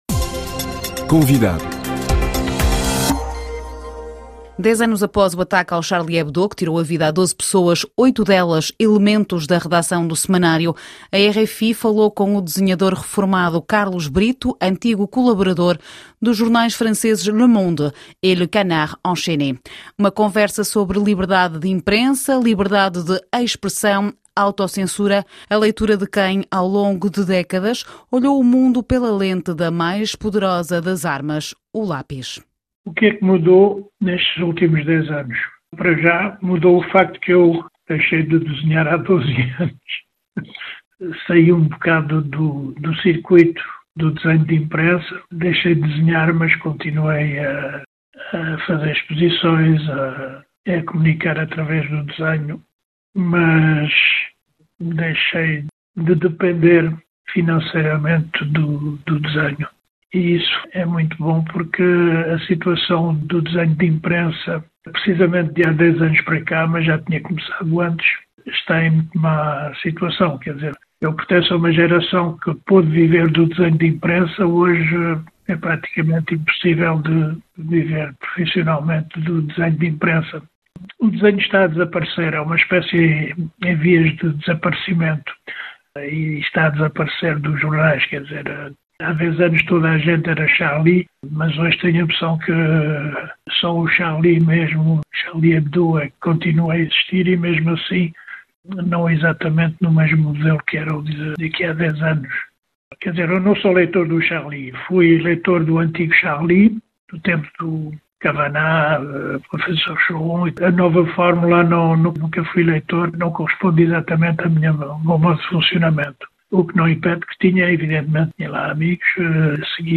RFI: O que é que mudou nestes dez anos?